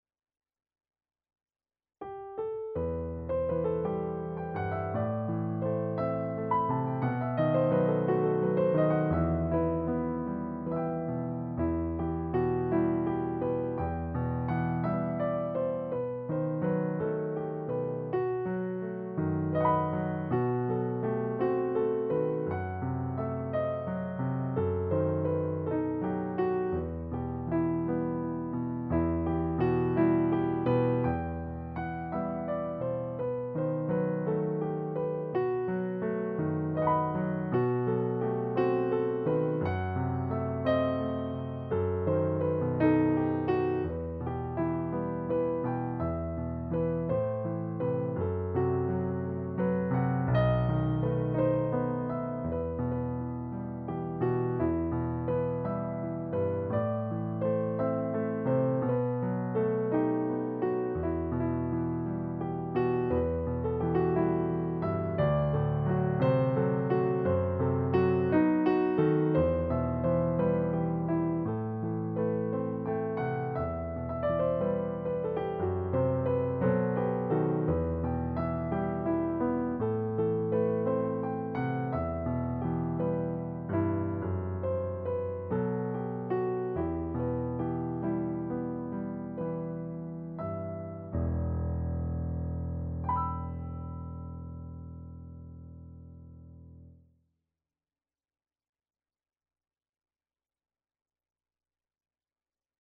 Piano s/ Clique